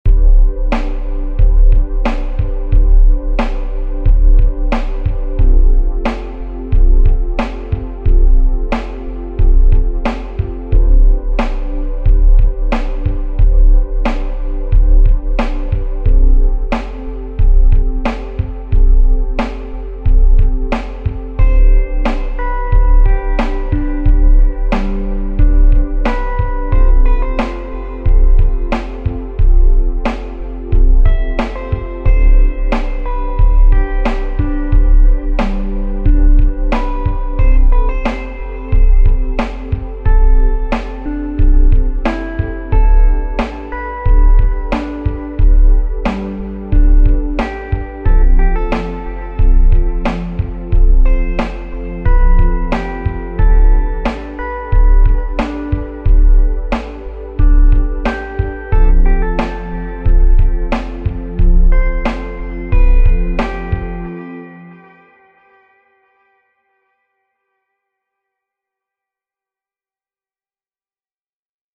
Romance – Free Stock Music